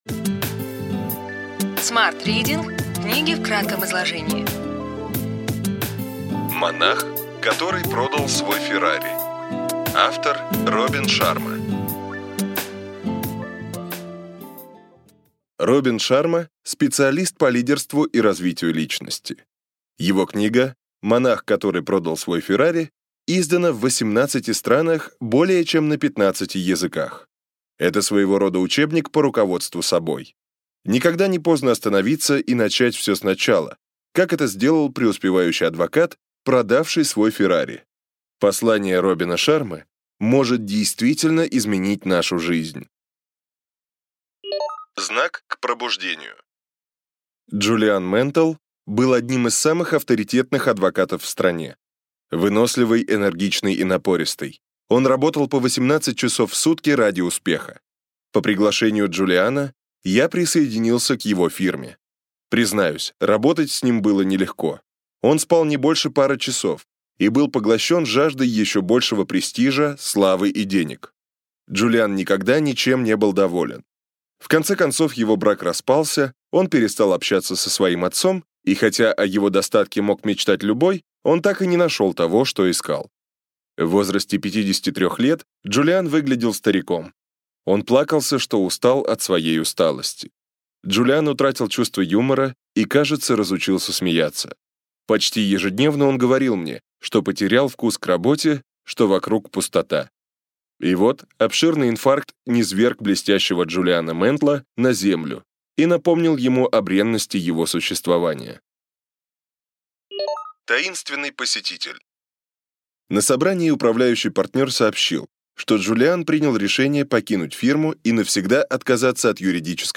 Аудиокнига Ключевые идеи книги: Монах, который продал свой «Феррари».